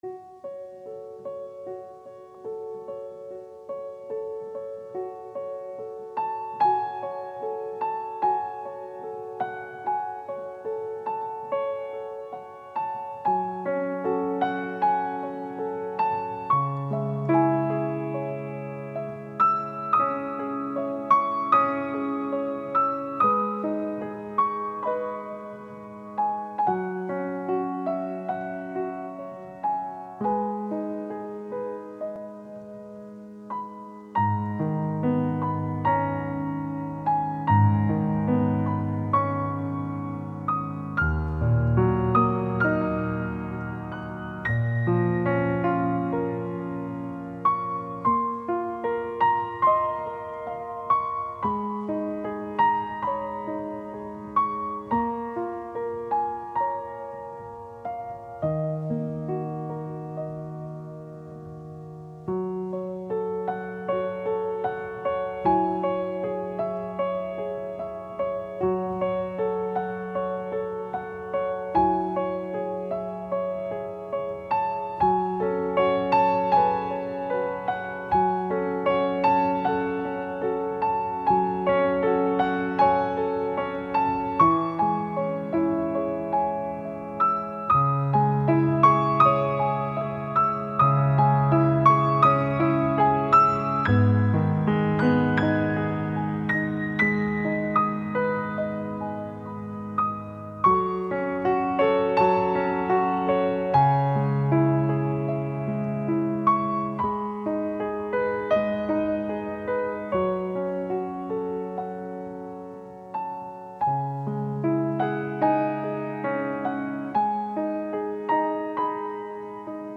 并且回到亲切的钢琴独奏从而表现出一种释然的情怀。